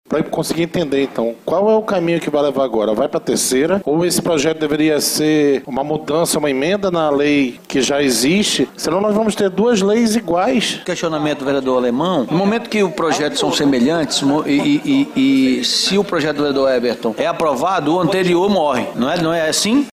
A situação deixou os vereadores confusos. (Ouça)